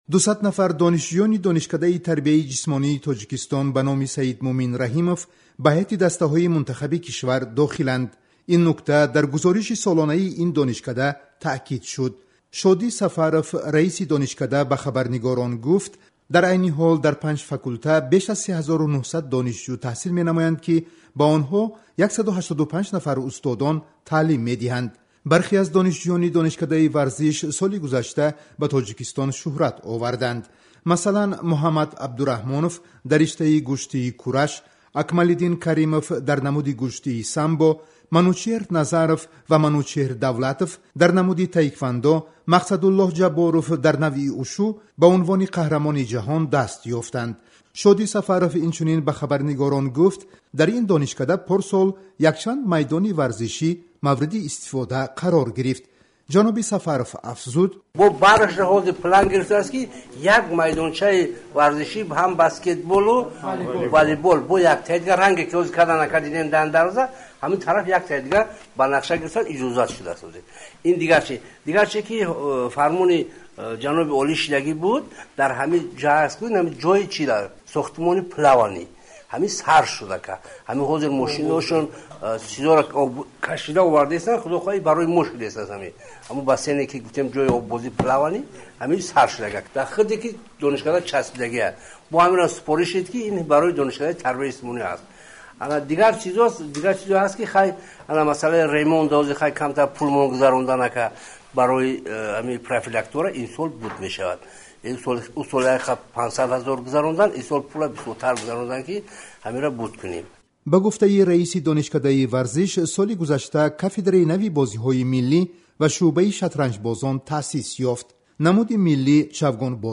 Нишасти хабарии раҳбарияти Донишкадаи варзиш